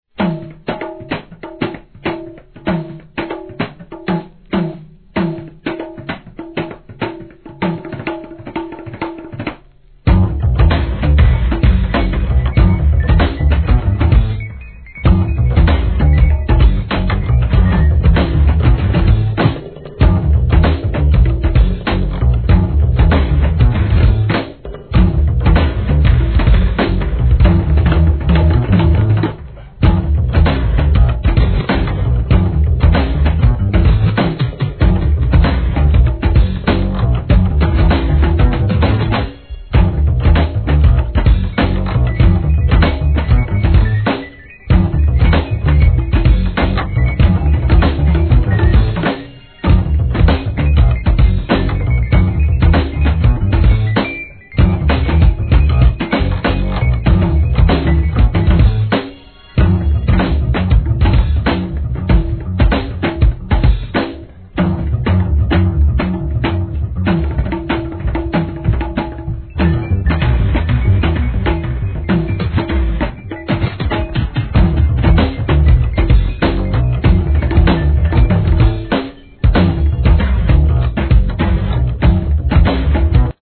HIP HOP/R&B
LIVE HIP HOP BAND